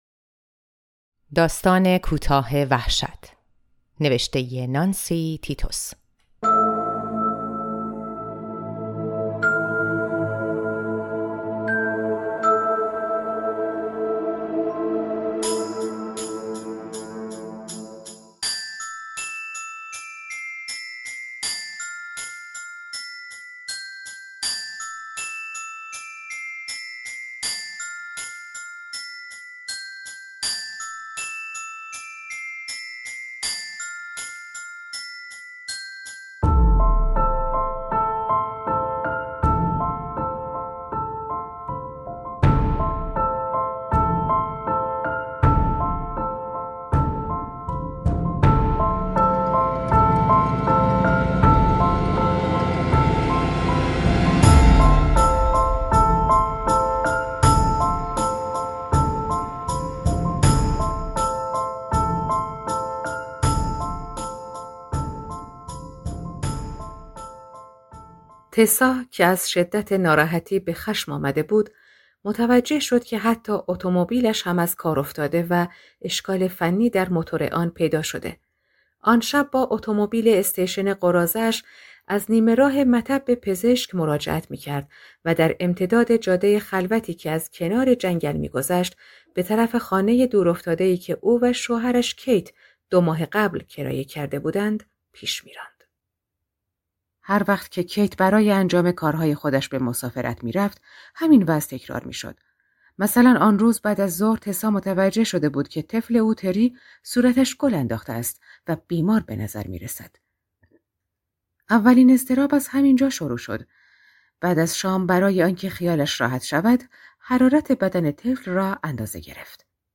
«نقشه برای قتل» مجموعه داستانی است شامل ۸ داستان کوتاه که به لحاظ “ساسپنس”، “روانشناسی” و اضطراب و هیجانی که ببار می‌آورد، می‌تواند از بهترین داستان‌های کوتاه شمرده شود. از بین آنها داستان کوتاه «وحشت» (به انتخاب آلفرد هیچکاک) اثر نانسی تیتوس را برایتان روایت می‌کنیم.